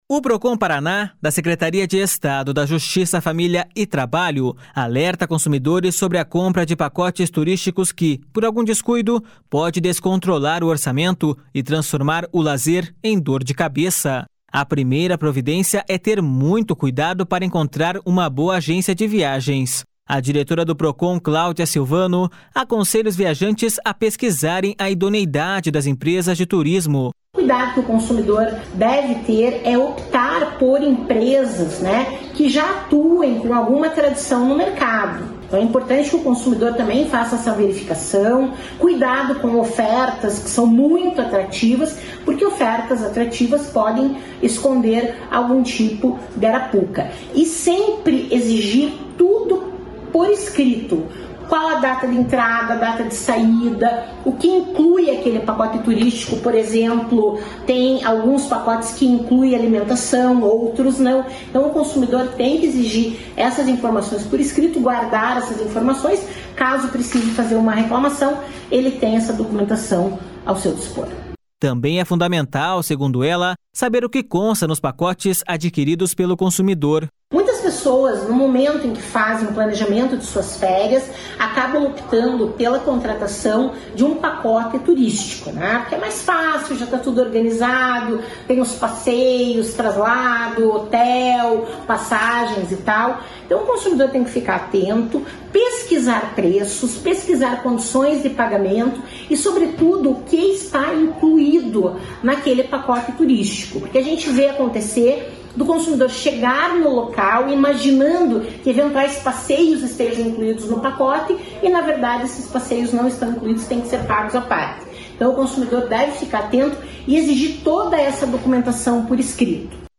A diretora do Procon, Cláudia Silvano, aconselha os viajantes a pesquisarem a idoneidade das empresas de turismo.// SONORA CLAUDIA SILVANO.//